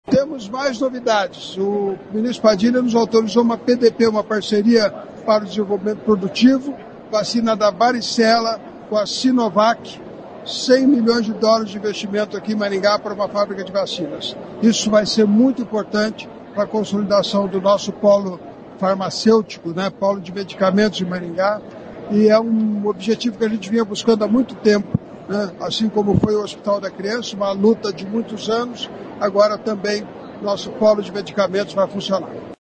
Em cerimônia de posse dos novos integrantes do Programa Saúde da Família, o deputado federal Ricardo Barros falou sobre a instalação de uma fábrica de vacinas contra a varicela em Maringá. Segundo o deputado, o ministro da Saúde, Alexandre Padilha, aprovou o projeto de Parceria para o Desenvolvimento Produtivo (PDP) para a instalação da fábrica, que prevê investimentos de US$ 100 milhões.